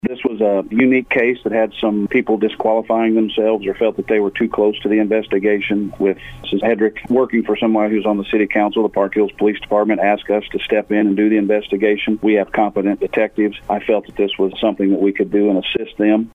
St. Francois County Sheriff Jeff Crites says the case is now moving forward.